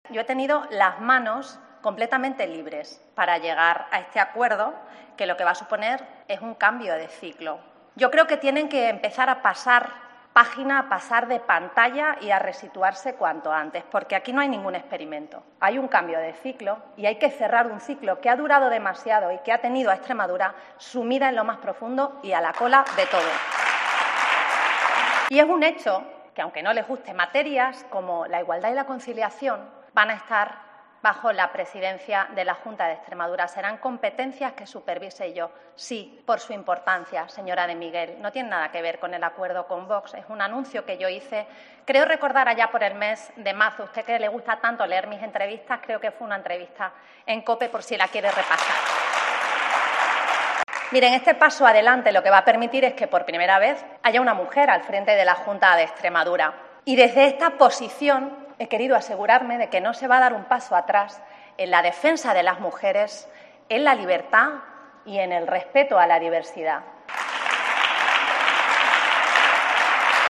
DEBATE INVESTIDURA EXTREMADURA
María Guardiola en su debate de investidura como presidenta de la Junta de Extremadura